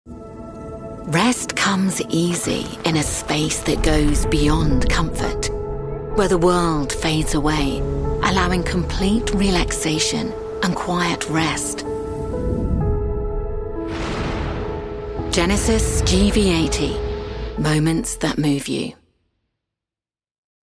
Female
English (British)
My voice has been described as friendly, rich, warm, relatable, and approachable.
Television Spots
Words that describe my voice are Relatable, Rich, Approachable.